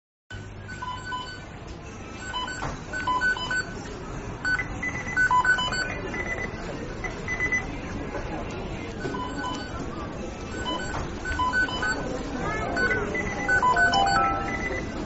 McDonalds Beeping Sound
mcdonalds-beeping-sound.mp3